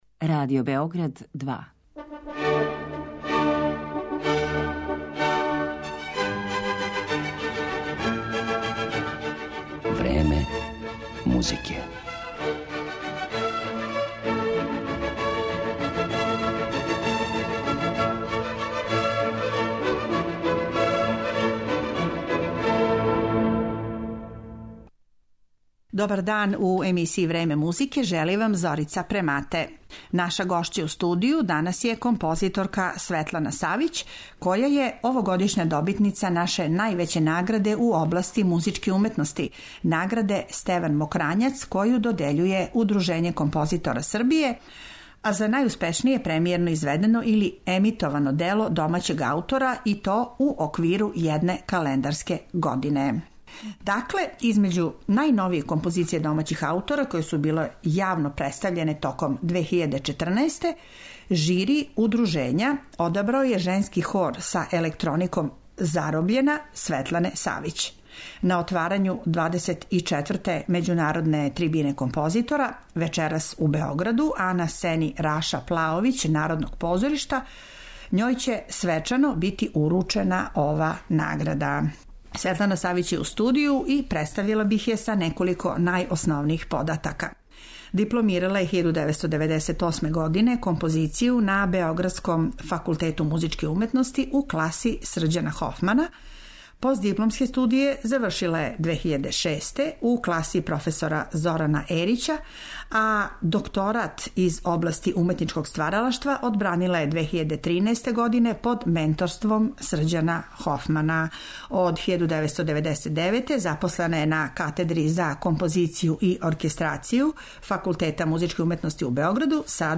за женски хор и електронику.